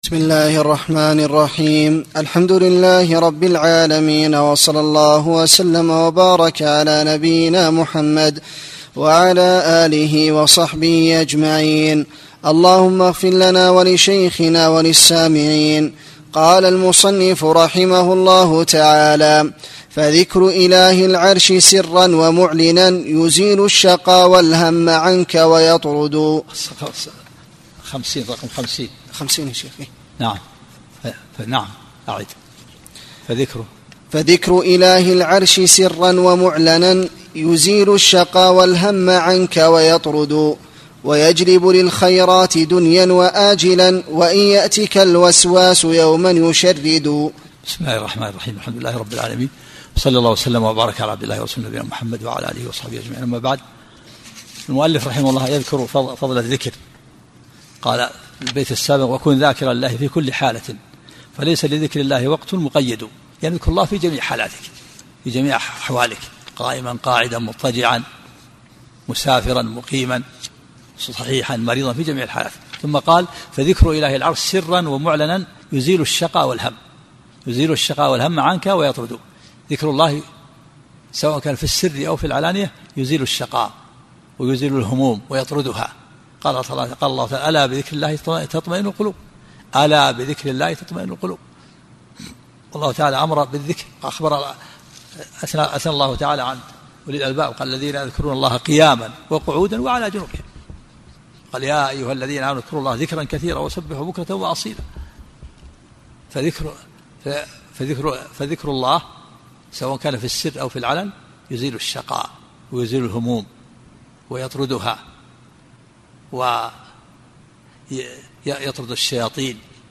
سلسلة محاضرات صوتية